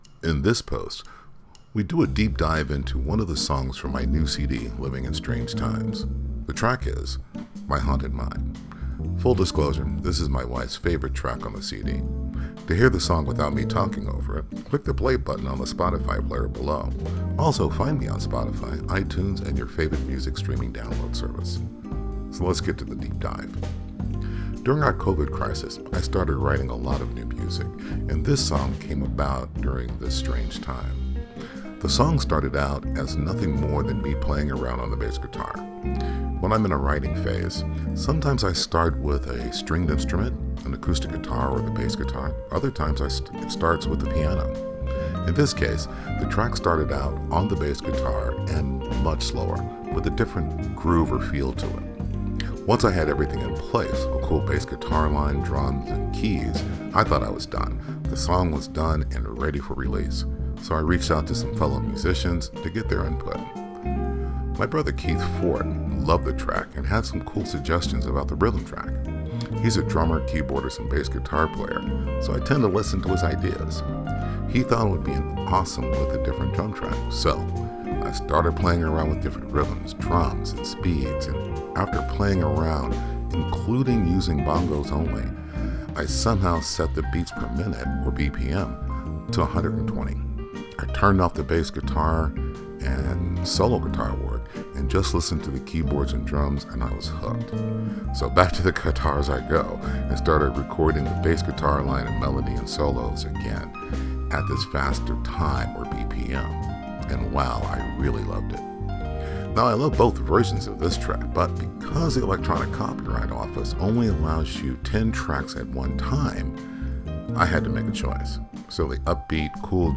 So, the upbeat/cool jazz version won out. The hock of the song came about accidentally while playing the bass guitar, so I decided to echo or repeat it using piano and melody guitar work. The track is written in the key of C minor which I must admit is one of my favorites.
The transition or chorus is in G minor but walks around a bit and drops you back with a nice transition chord that leads to the C minor.